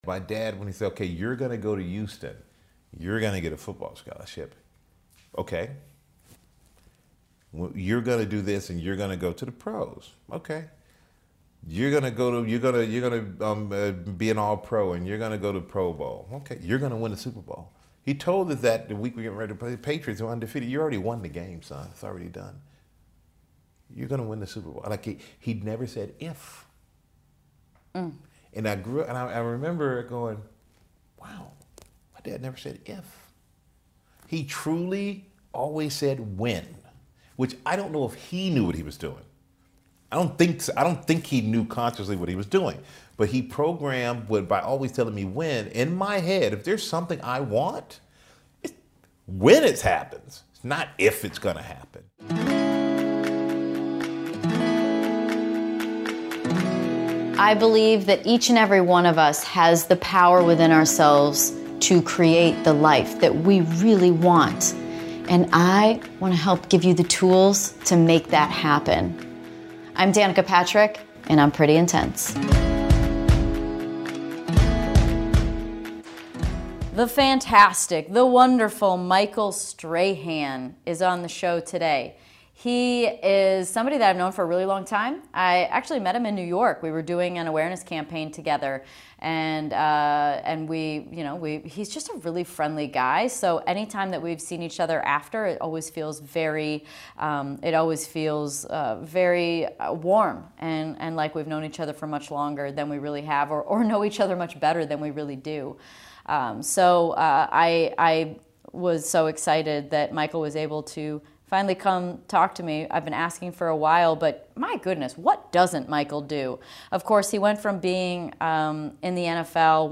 In this interview, learn about his level of awareness, of the power of the mind and how he's used it to create personal improvement in every aspect of his life.